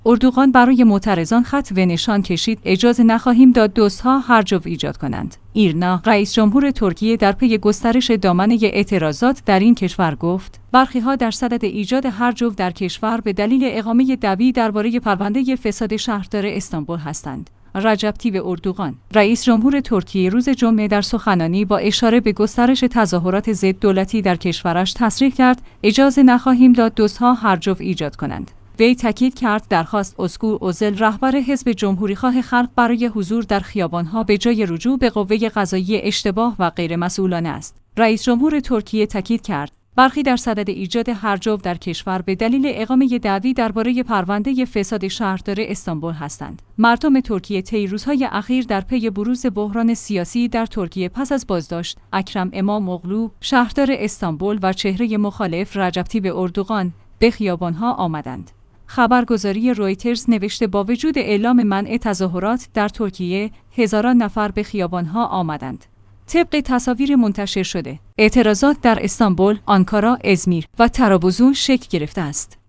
ایرنا/ رئیس‌جمهور ترکیه در پی گسترش دامنه اعتراضات در این کشور گفت، برخی‌ها درصدد ایجاد هرج و مرج در کشور به دلیل اقامه دعوی درباره پرونده فساد شهردار استانبول هستند. «رجب طیب اردوغان»، رئیس‌جمهور ترکیه روز جمعه در سخنانی با اشاره به گسترش تظاهرات ضد دولتی در کشورش تصریح کرد: اجازه نخواهیم داد دزدها